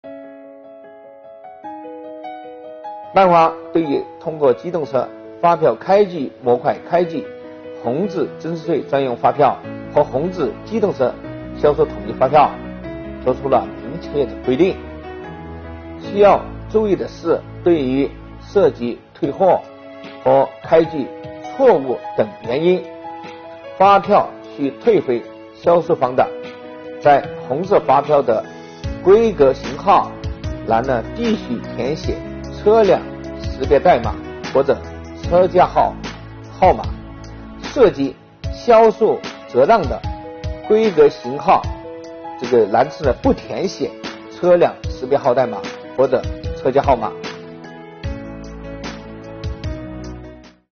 近日，国家税务总局推出“税务讲堂”课程，国家税务总局货物和劳务税司副司长张卫详细解读《办法》相关政策规定。